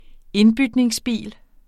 indbytningsbil substantiv, fælleskøn Bøjning -en, -er, -erne Udtale [ ˈenbydneŋs- ] Betydninger brugt bil der gives i bytte som en del af handlen når man køber ny bil Den nye, brugte bil bliver dyrere.